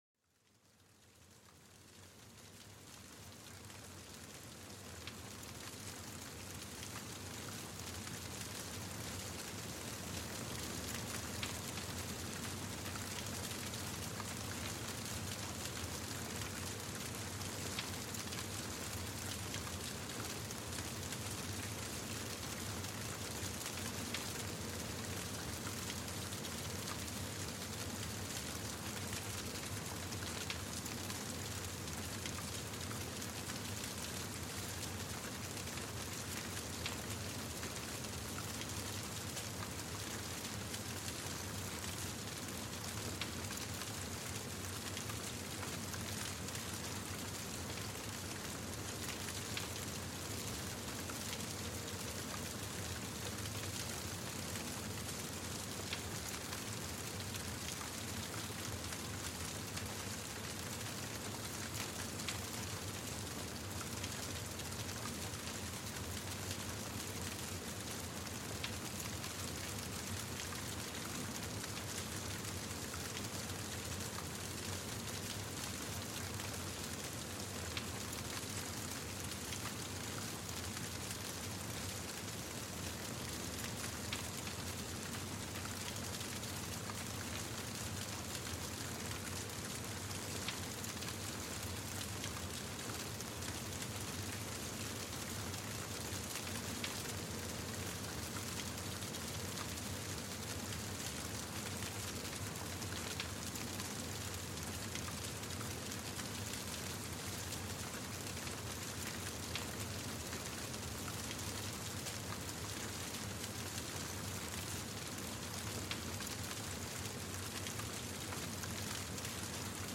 La Chaleur Apaisante du Feu : Découvrez les Bienfaits Relaxants du Crépitement des Flammes